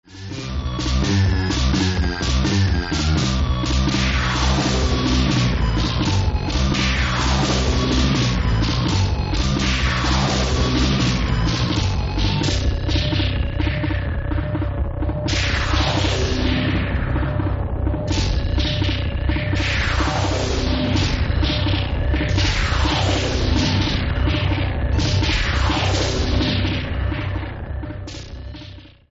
Stilbildend und vorbildlich für Analog und Lo-Tec-Fans.